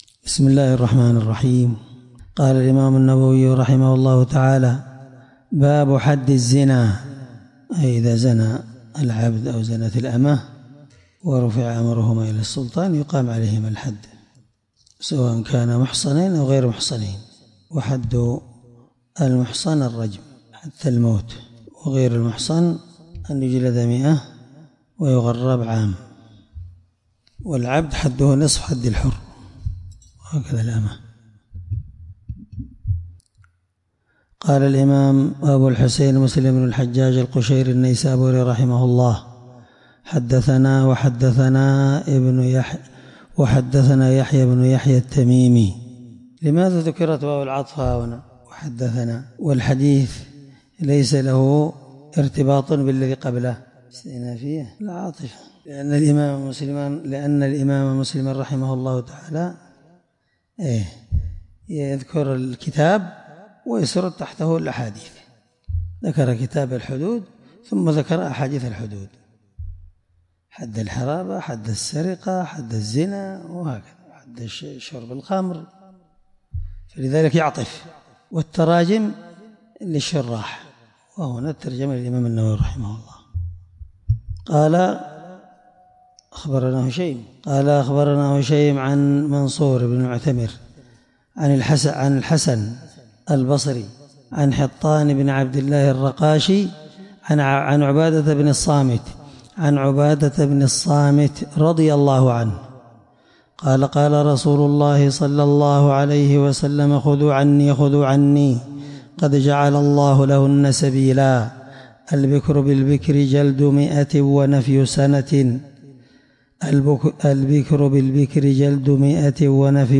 الدرس3من شرح كتاب الحدود حديث رقم(1690) من صحيح مسلم